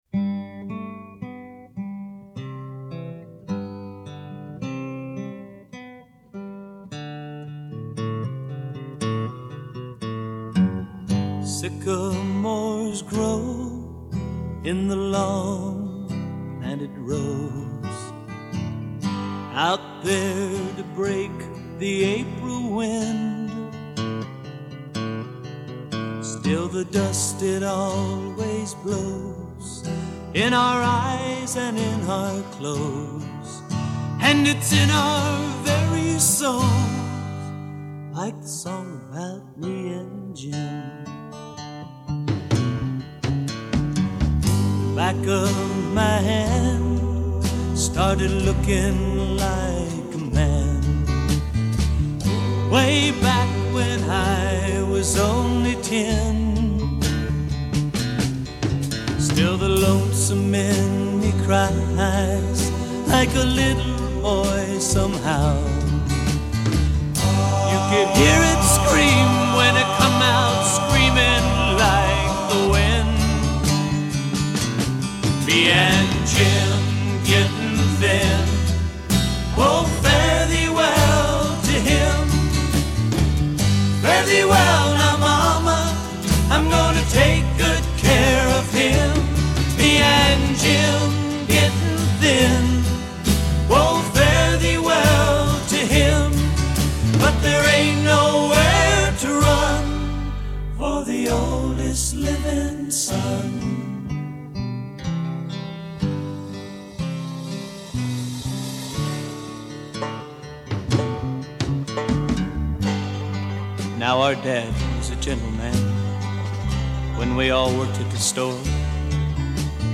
banjo